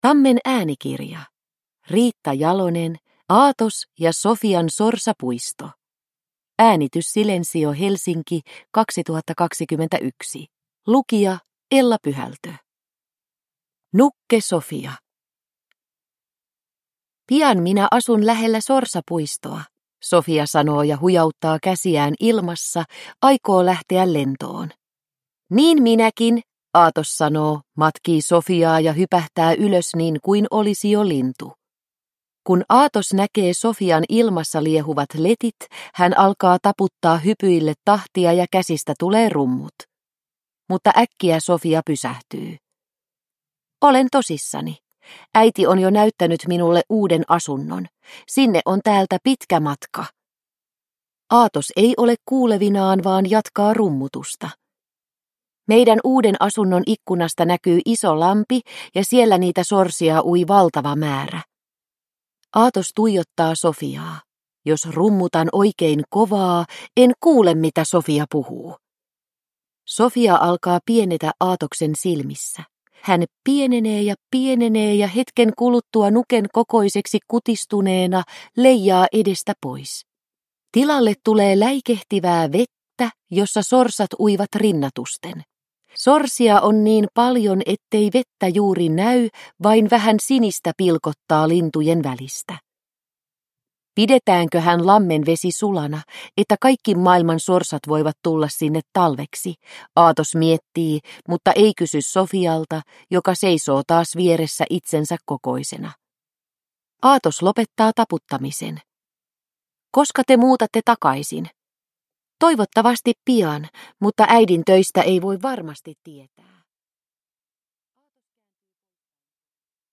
Aatos ja Sofian sorsapuisto – Ljudbok – Laddas ner